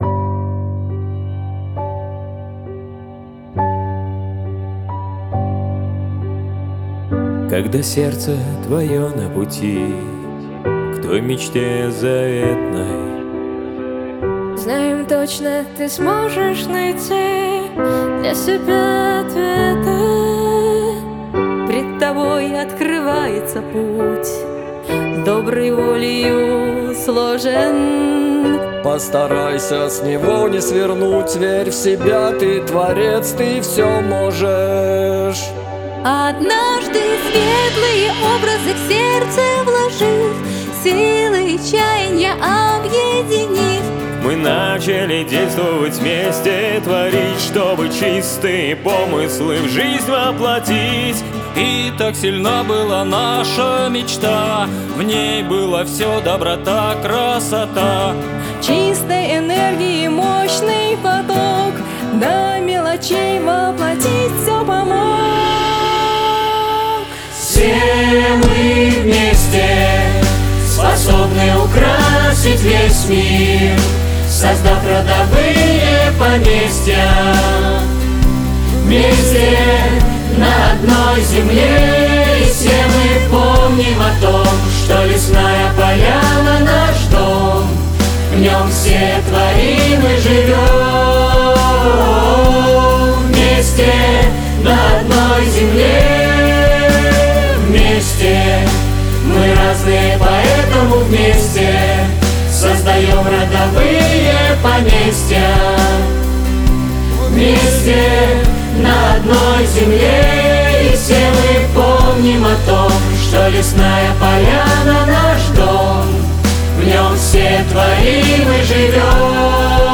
В 2023 году у нашего поселения появилась своя песня, которую леснополянцы сочинили и записали сами: